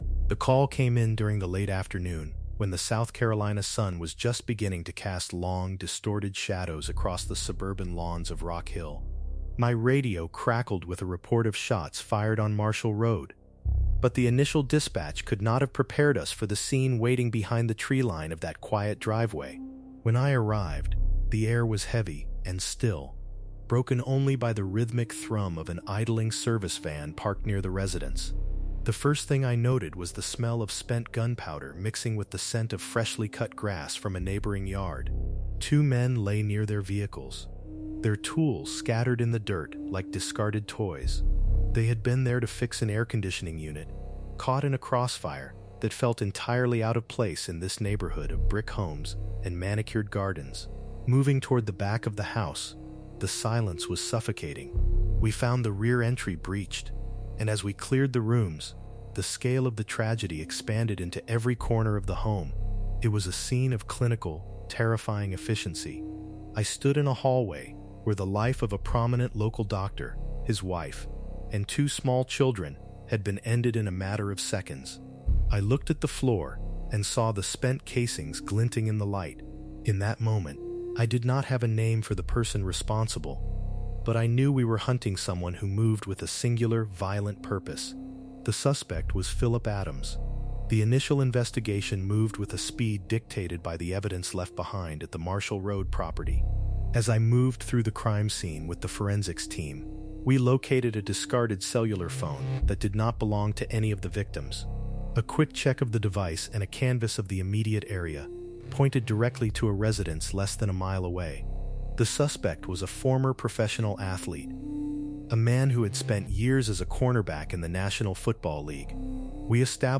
This full investigation explores the tragic case of Phillip Adams, a former NFL cornerback whose sudden descent into violence shocked the community of Rock Hill, South Carolina. In this true-crime documentary, we reconstruct the timeline of the Marshall Road shooting, examining the forensic evidence and the chilling discovery made by investigators at the scene.